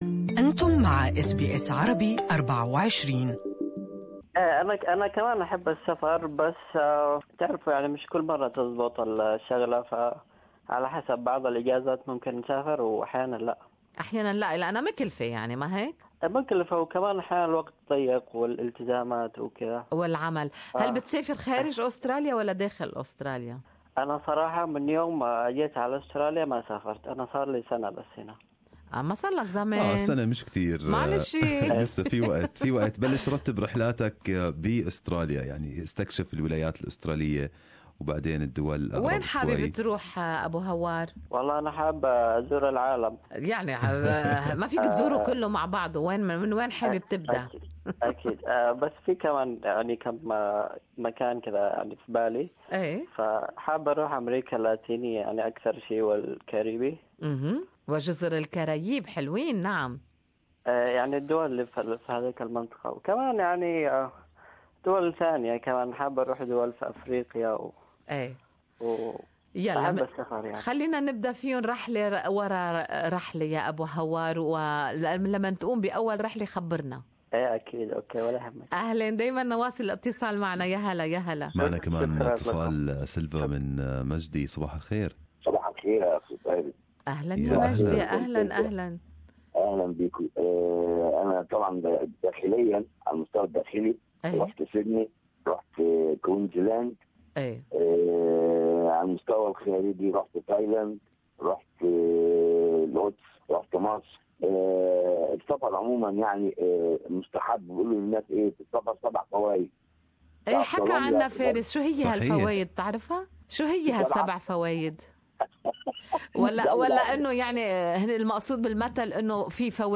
Good Morning Australia's talkback on the next vacation destination.